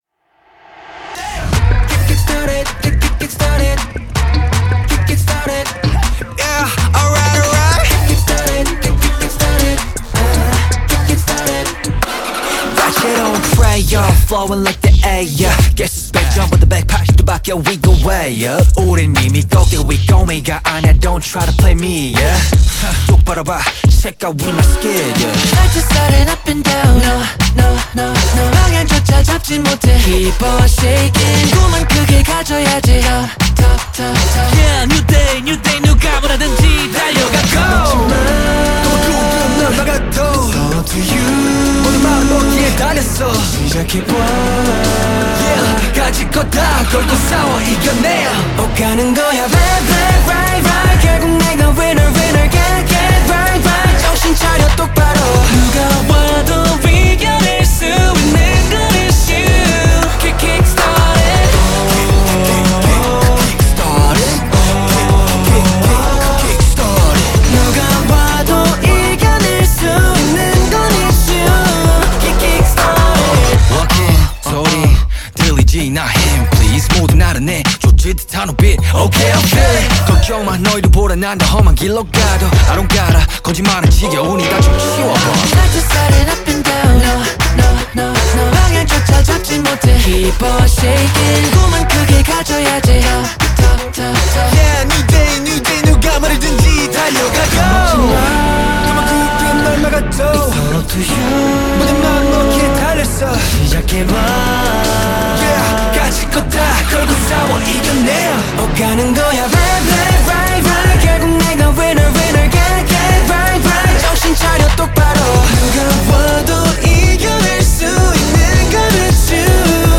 KPop Song
Label Dance